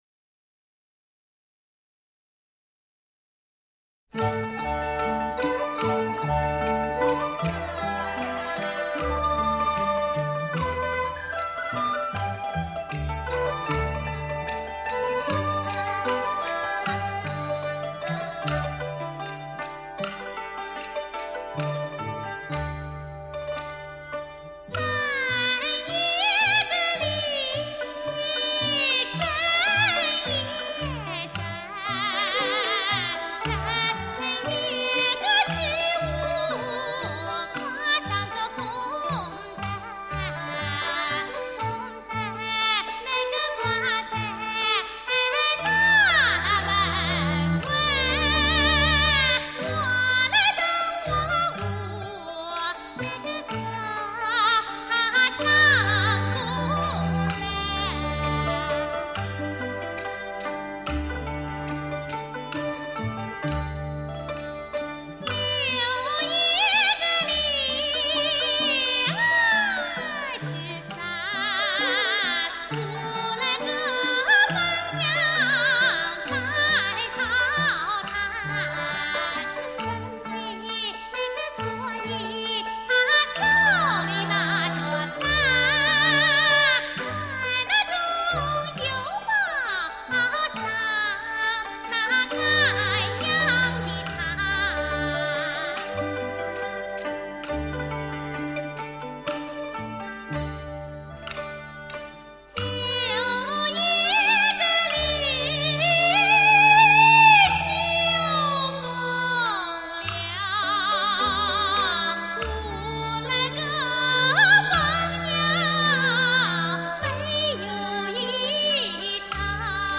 浓郁的乡土气息，清清的田野之风
脆亮、质朴醇厚的绝佳音色。